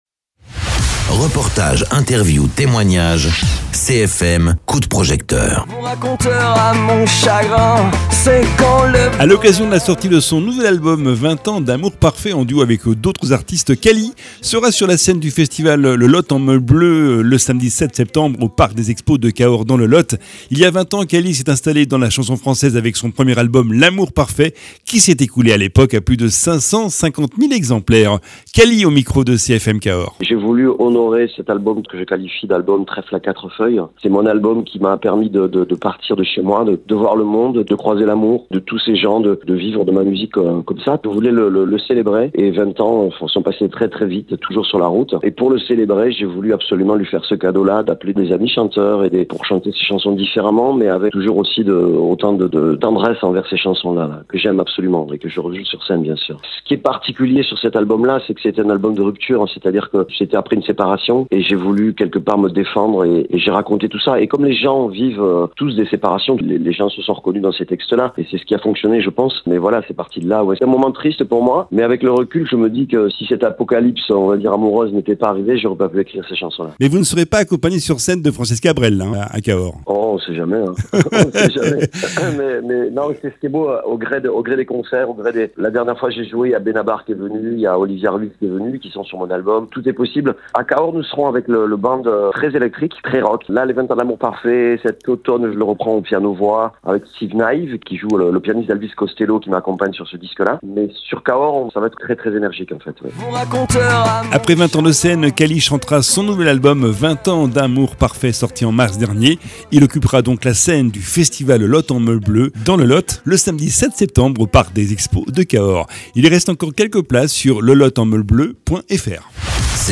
Interviews
Invité(s) : CALI, auteur, compositeur, interprète